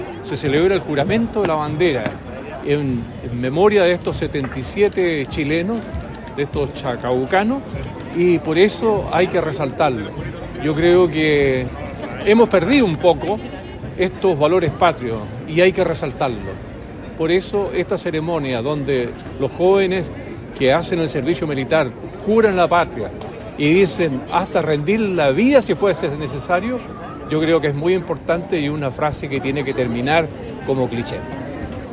Comprometidos con la patria, 75 soldados del Destacamento de Montaña N°8 “Tucapel” realizaron el juramento a la bandera en un acto conmemorativo desarrollado en la Plaza Aníbal Pinto, rindiendo un solemne homenaje al pabellón nacional, en el marco de la conmemoración del Combate de la Concepción y el Juramento a la Bandera realizado por suboficiales, soldados de tropa profesional y soldados conscriptos del Destacamento.
El concejal, Mario Jorquera, destacó la importancia de revalorizar las tradiciones militares, afirmando que, “hemos perdido un poco nuestros valores patrios.